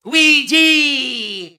Luigi's voice from the character select screen from Mario Kart 8.
MK8_Luigi_-_Character_Select.oga.mp3